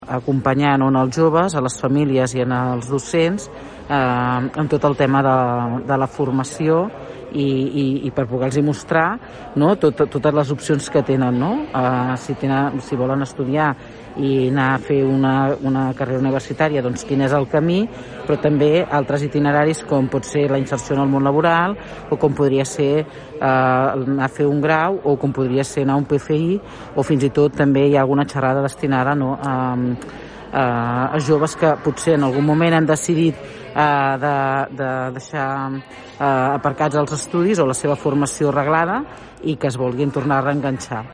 A les 11 del matí s’ha dut a terme l’acte oficial d’inauguració de les activitats, al Teatre de Palafolls, en què s’ha presentat la iniciativa.
Des del Teatre palafollenc, la regidora d’Educació Susanna Pla ha destacat la diversitat d’opcions que posa damunt la taula aquest projecte.